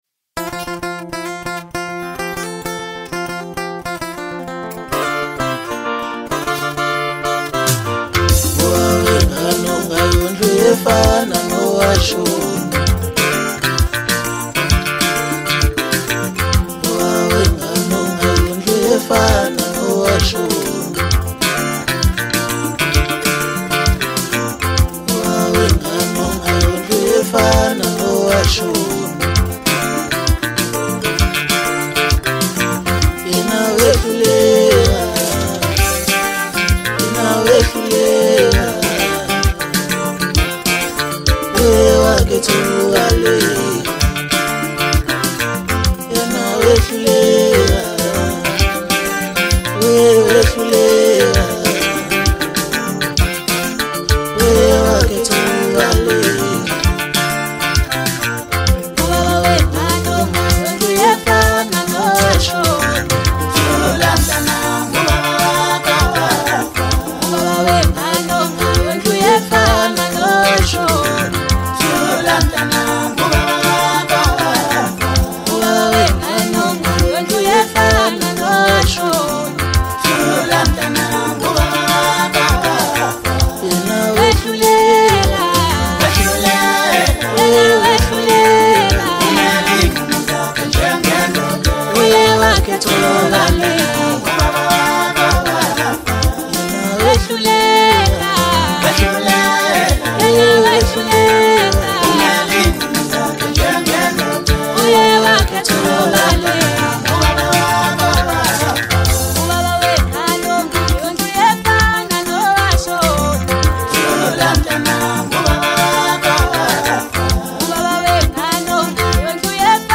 Home » Maskandi » DJ Mix
South African singer